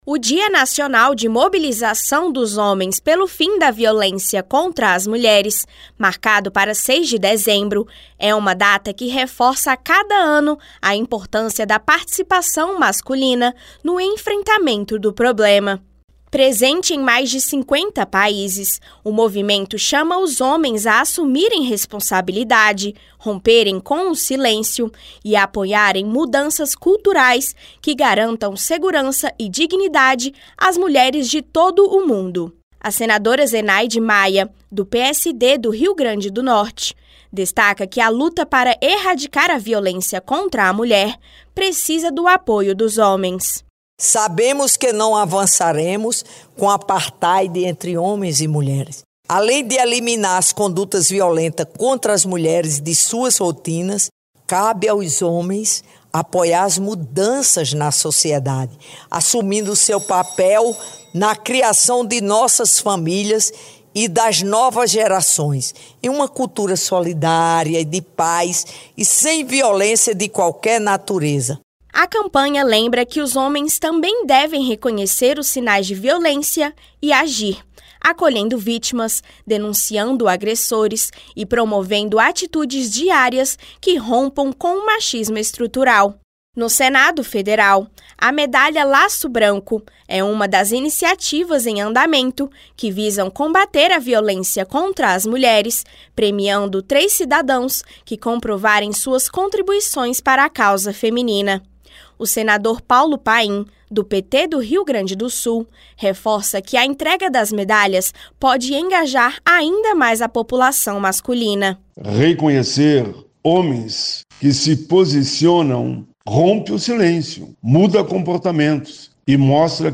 A Campanha Laço Branco mobiliza homens no enfrentamento à violência contra as mulheres e integra os "21 dias de ativismo pelo fim da violência contra a mulher". A senadora Zenaide Maia, do PSD do Rio grande do norte e o senador Paulo Paim, do PT do Rio Grande do Sul reforçam o papel de toda a sociedade na construção de uma cultura de paz.